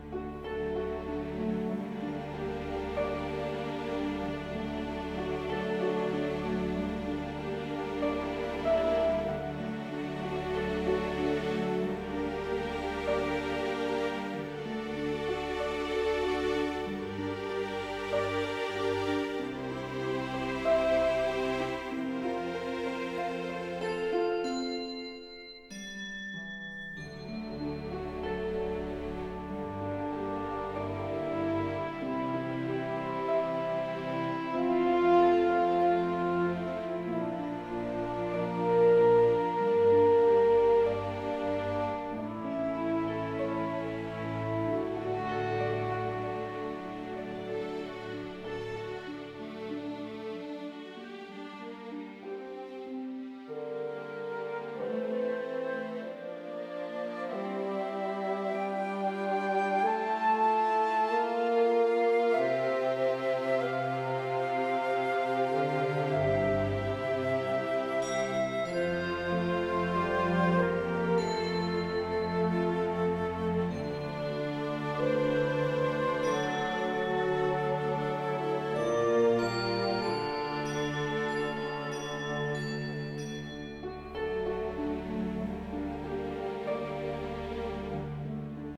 relaxed.ogg